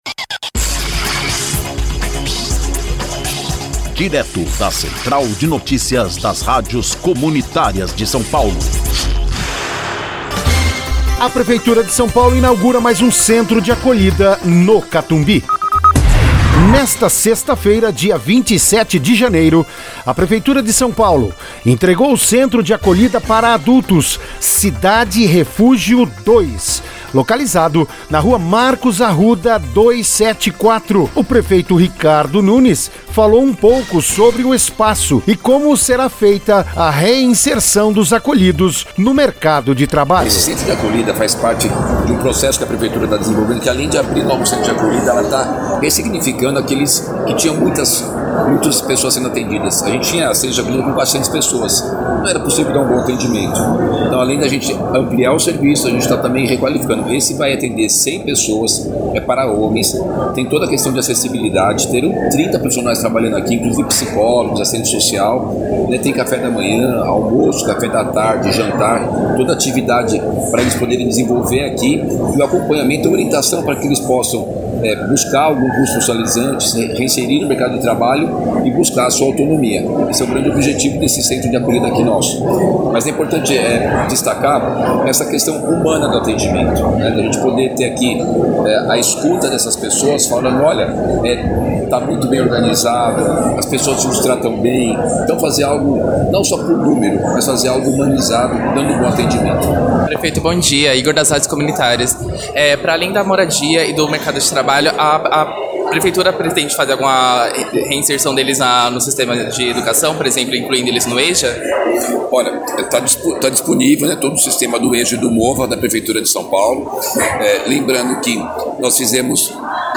REPORTAGEM
O prefeito Ricardo Nunes falou um pouco sobre o espaço e como ele será feita a reinserção dos acolhidos no mercado de trabalho: